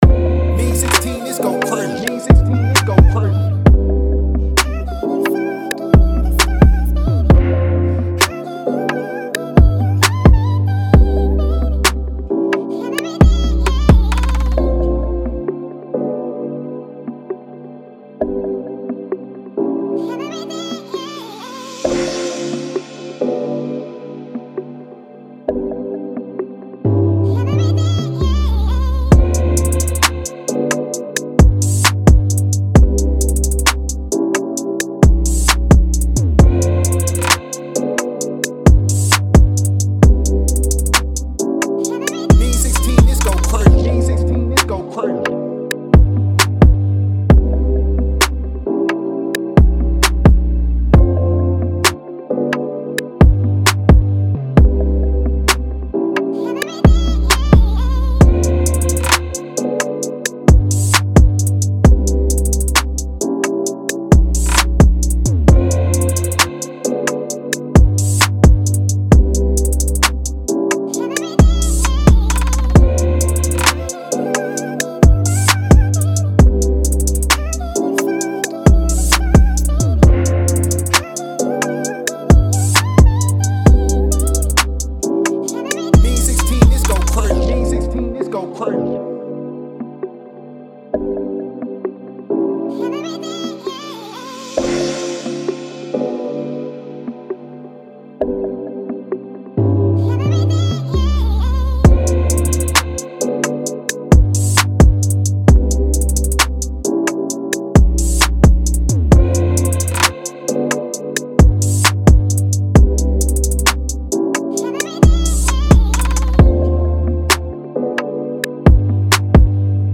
C-Min 132-BPM